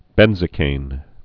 (bĕnzə-kān)